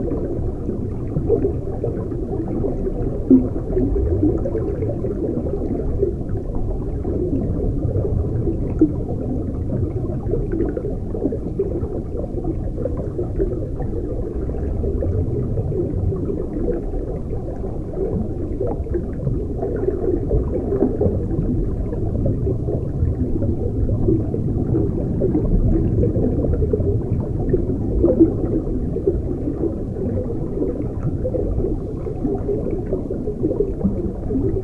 underwater_sea_diving_bubbles_loop_03.wav